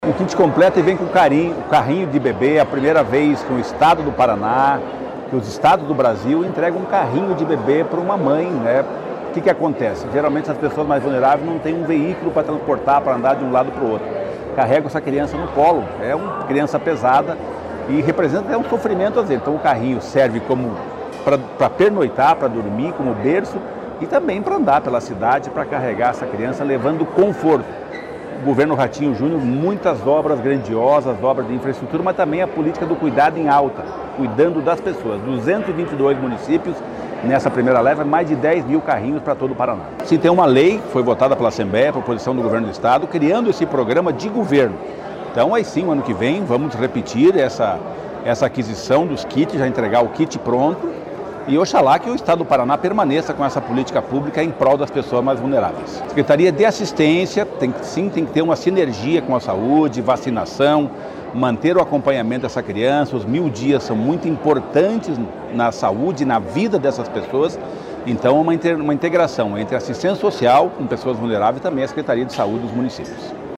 Sonora do secretário do Desenvolvimento Social e Família, Rogério Carboni, sobre o programa Nascer Bem Paraná